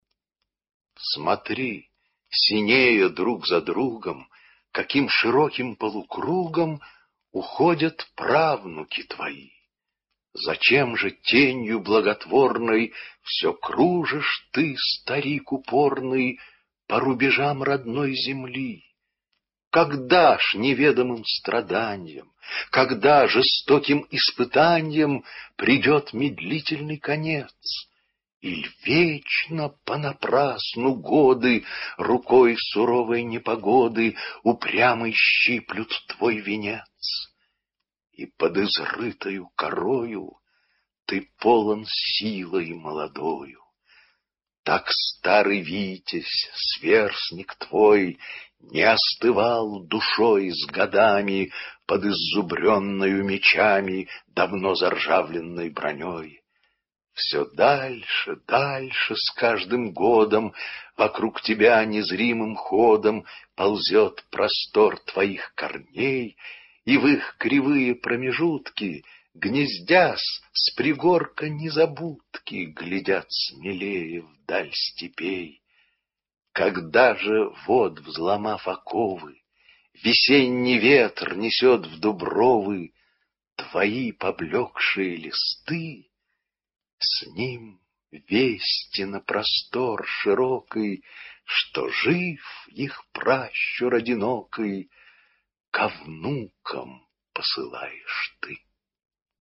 2. «Фет Афанасий – Одинокий дуб (читает Яков Смоленский)» /
fet-afanasij-odinokij-dub-chitaet-yakov-smolenskij